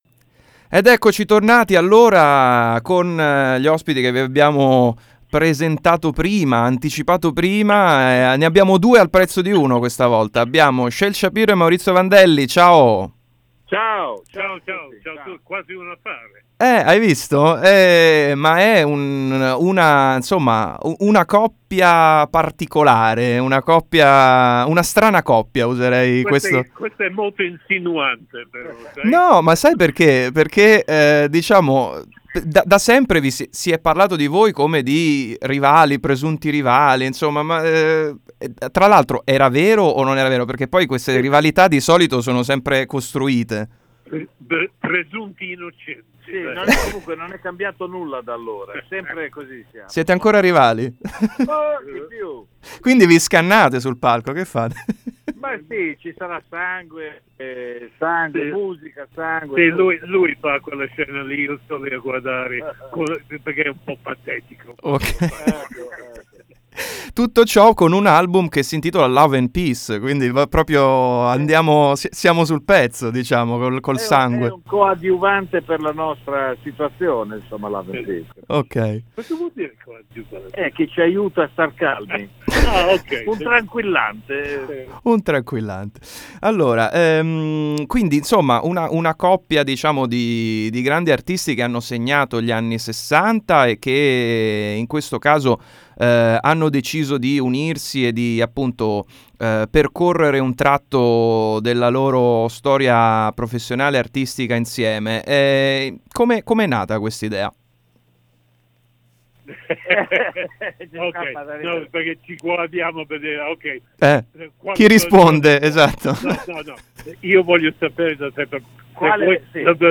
Intervista a Shel Shapiro e Maurizio Vandelli 19/11/2018 | Radio Città Aperta